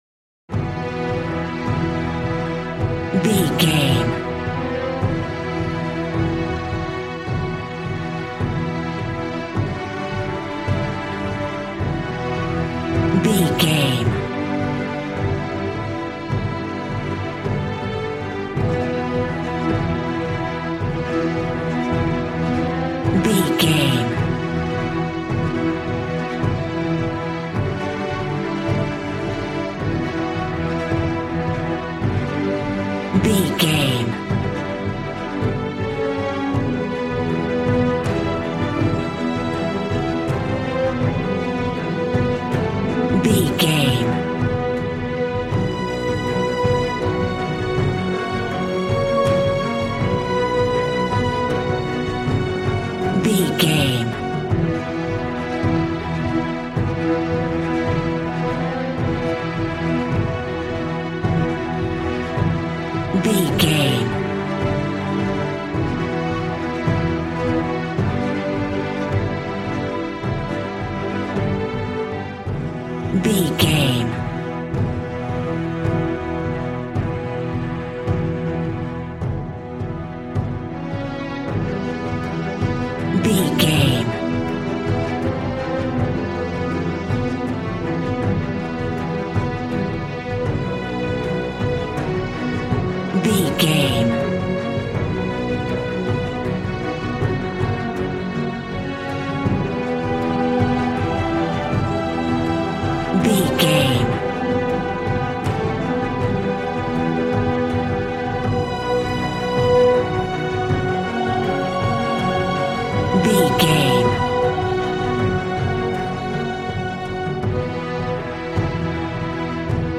Ionian/Major
dark
suspense
piano
synthesiser